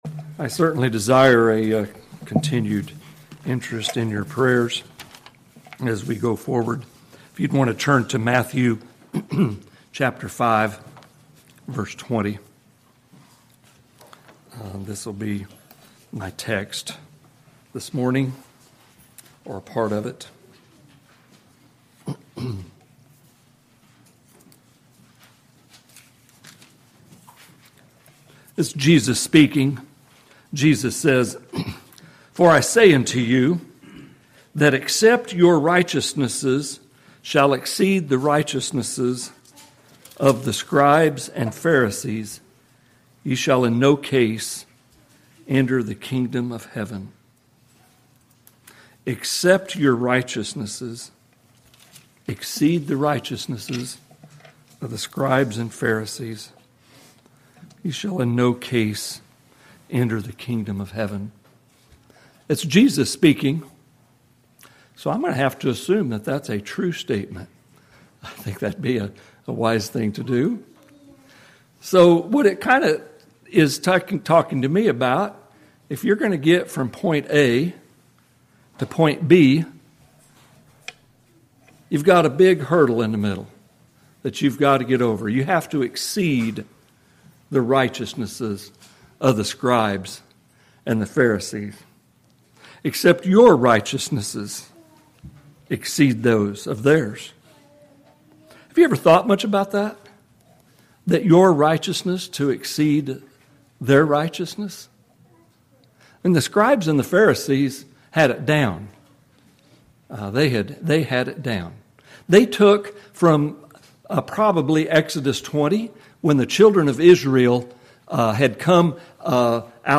In this message our associate pastor